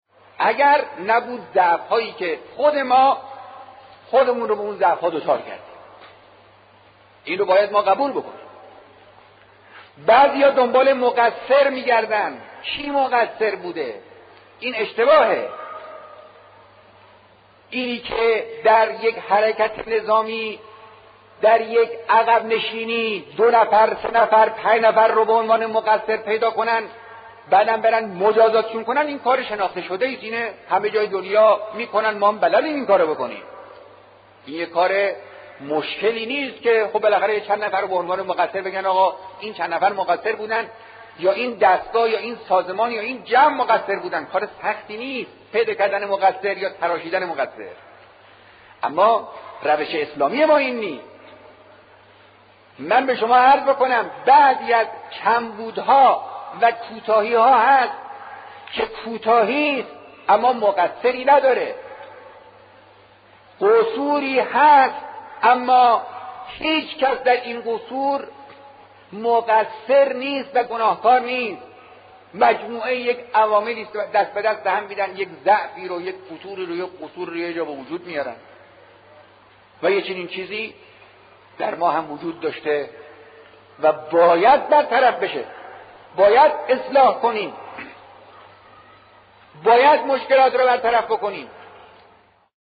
آیت‌الله خامنه‌ای در خطبه‌های نمازجمعه‌ی ۱۰ تیرماه ۱۳۶۷ با اشاره به همین موضوع چنین می‌گویند: «اگر نبود ضعف‌هایى که خود ما، خودمان را به آن ضعف‌ها دچار کردیم.